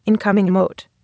incoming_emote.wav